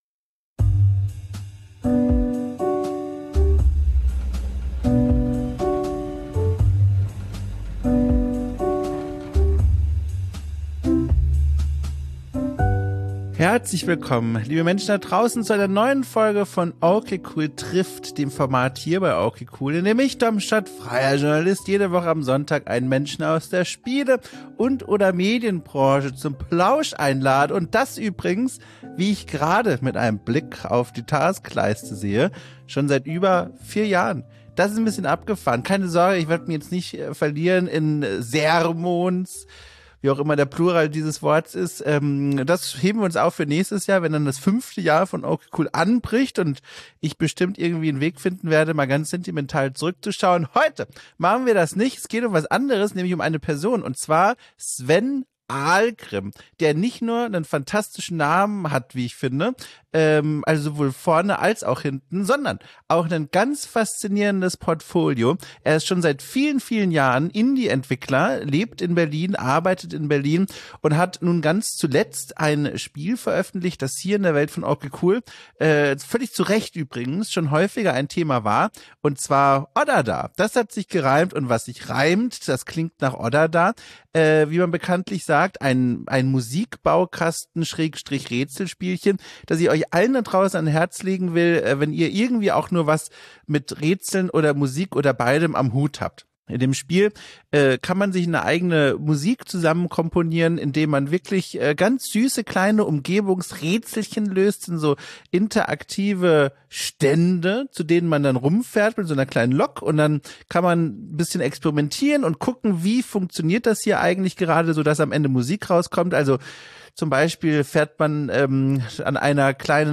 Ein Gespräch über die Arbeit und das Leben drumrum